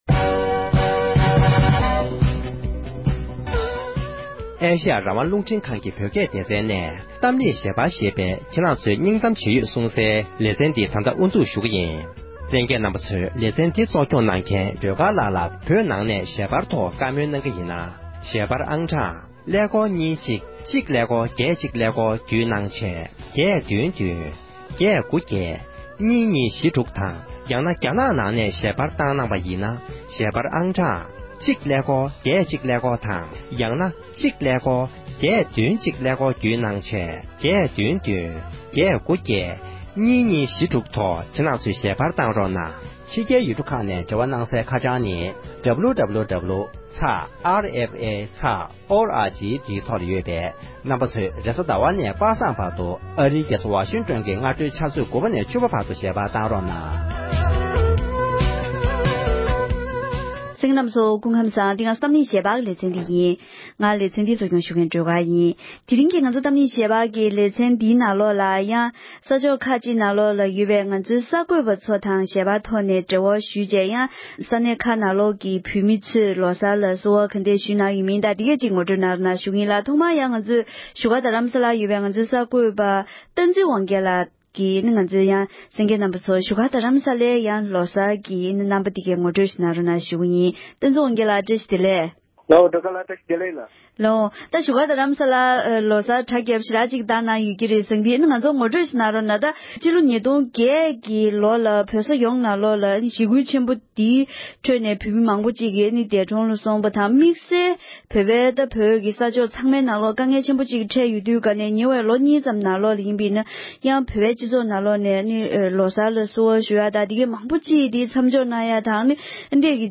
བཙན་བྱོལ་ནང་ཡོད་པའི་བོད་མི་ཚོས་བོད་ཀྱི་ལོ་གསར་ལ་བསུ་བ་ཇི་འདྲ་ཞུས་ཡོད་མིན་ཐད་བཀའ་མོལ་ཞུས་པ།